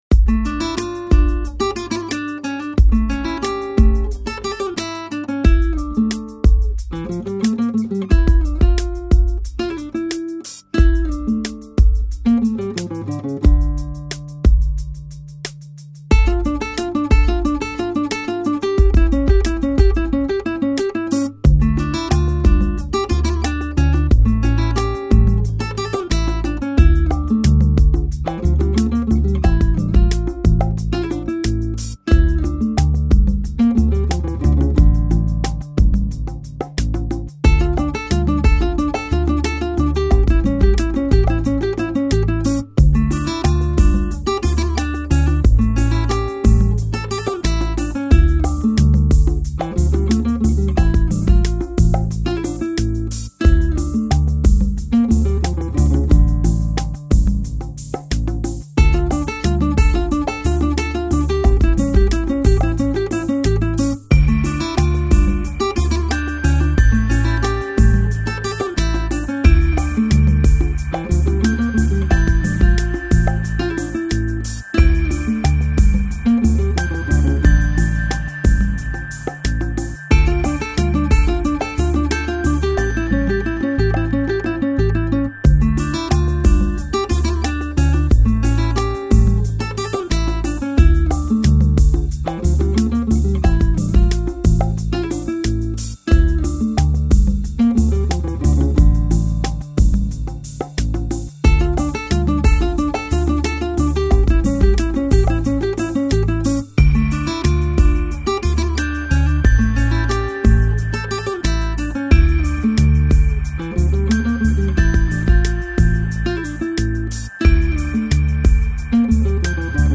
Electronic, Lounge, Chill Out, Downtempo Год издания